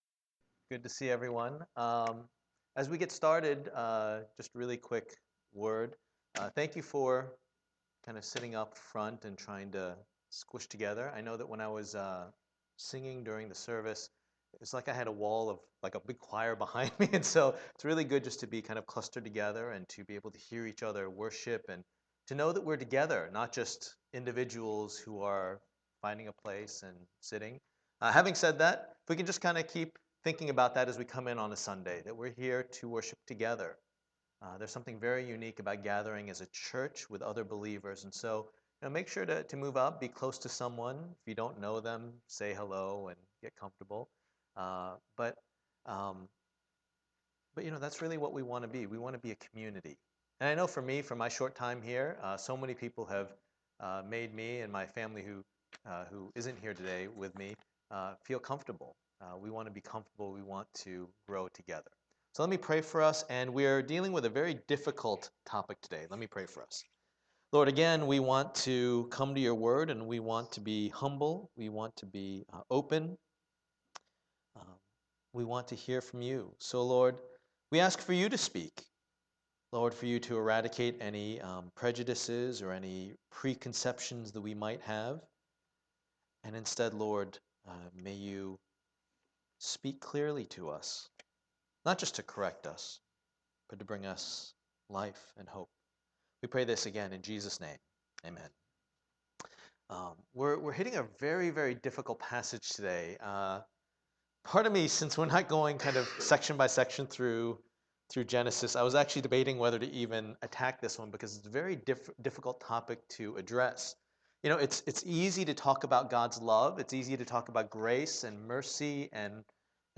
Genesis 9:8-17 Service Type: Lord's Day %todo_render% « Sin